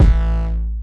UPGRADE 2 808 (4).wav